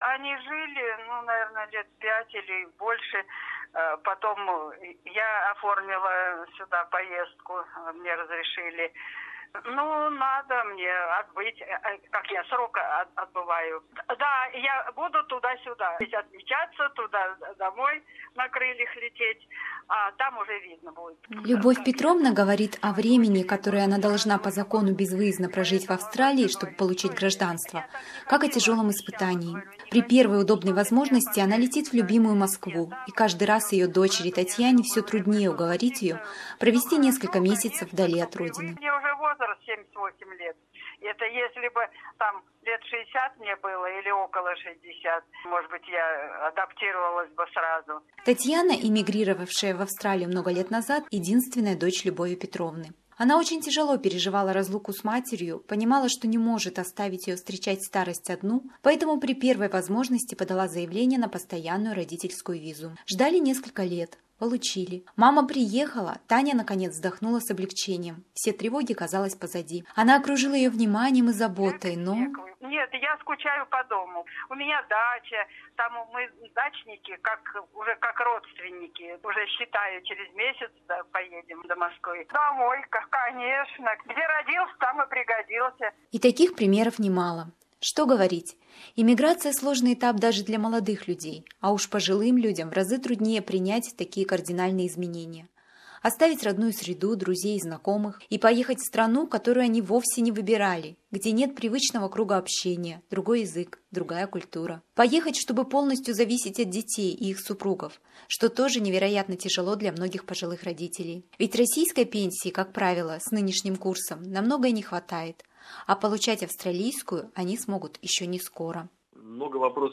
In our report - two completely different stories of immigration, with immigration agent explanation on the parents visas and other useful information